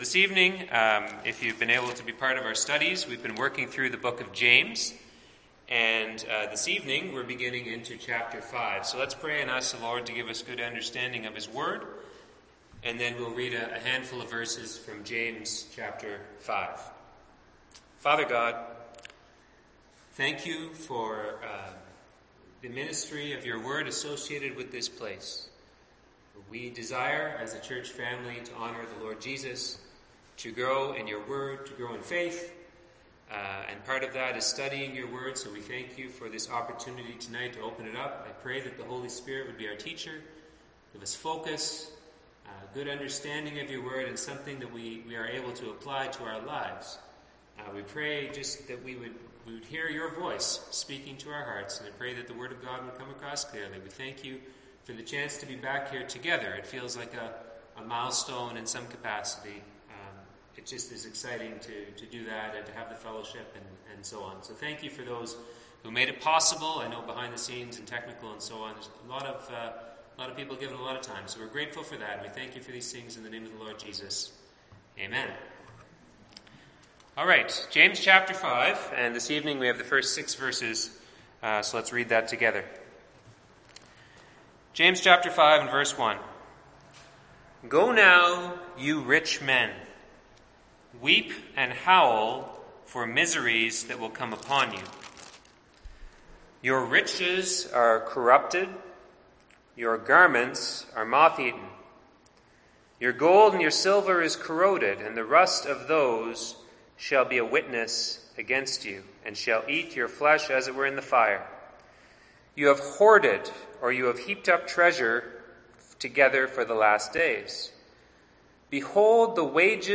Passage: James 5:1-6, Luke 16:1-9 Service Type: Seminar